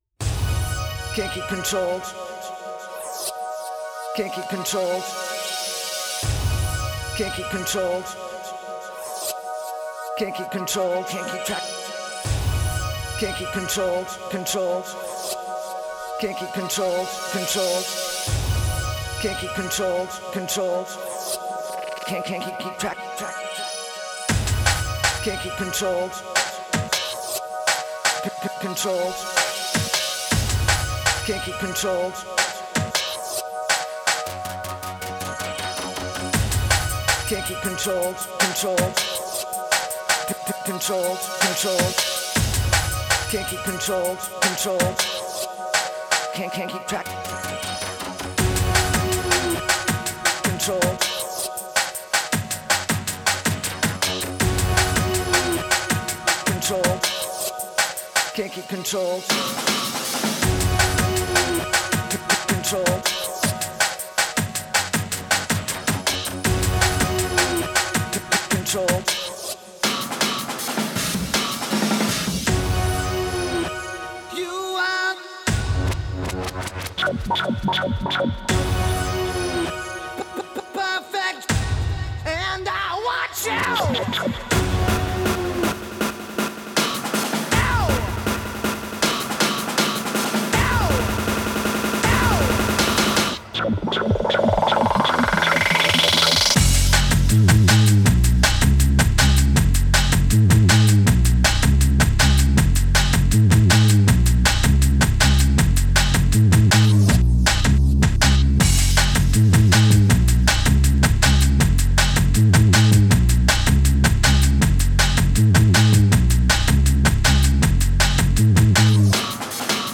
Remixes
promo vinyl